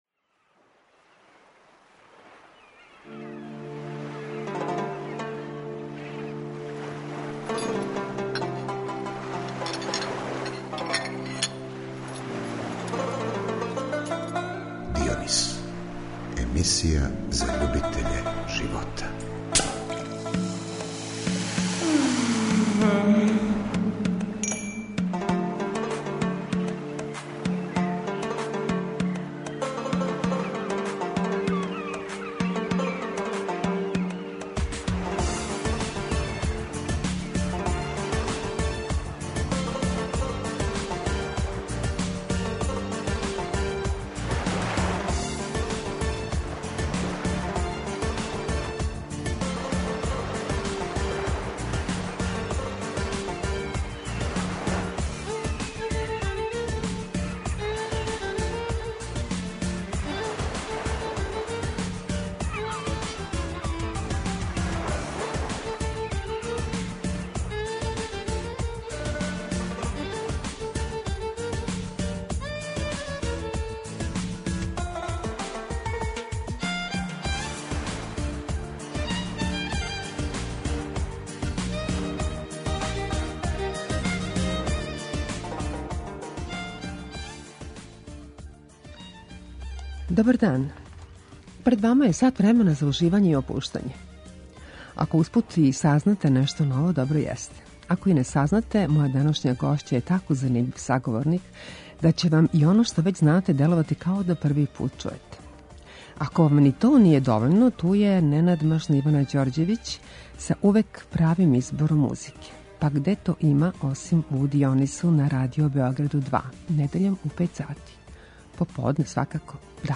Емисија за љубитеље живота